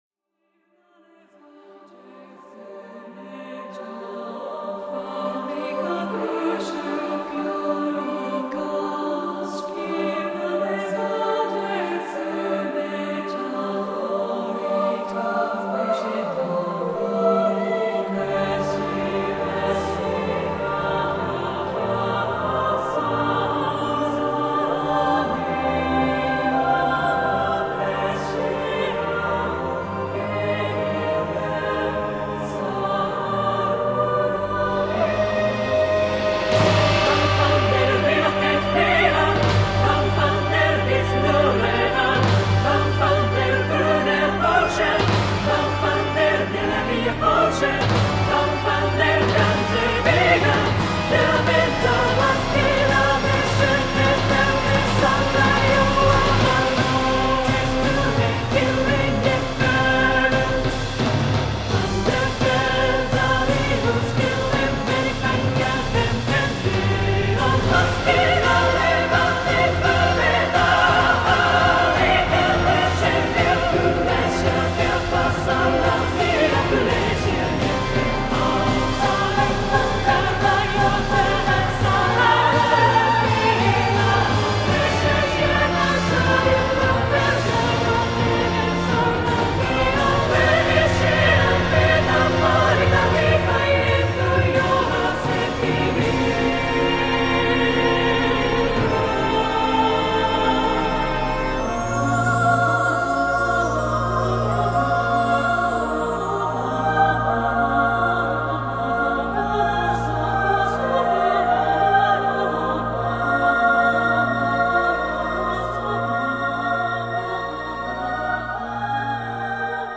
社内でも、ボス戦が超荘厳になりテストプレイでみんなで盛り 上がっていました。